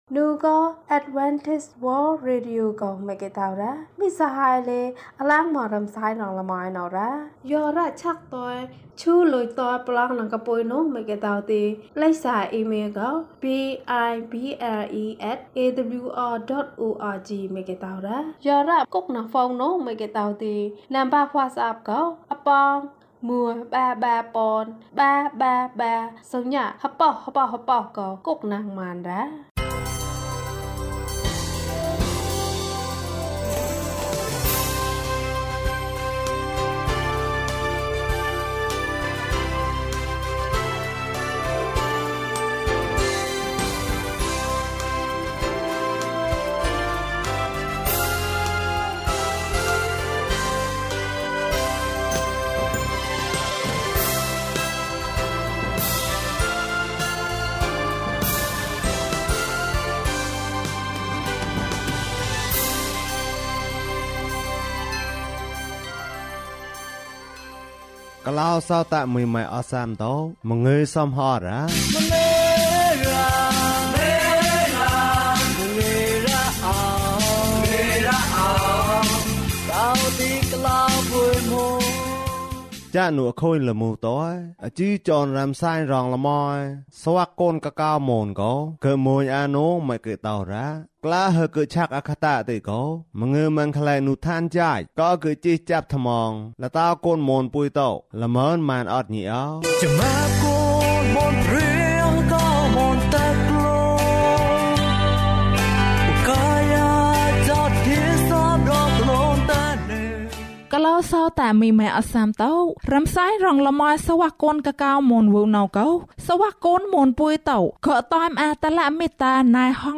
ဂတးမုက်ကျာ်မၞးဂှ်မၞးထံက်ကွံၚ်ညိ။ ဓမ္မသီချင်း။ တရားဒေသနာ။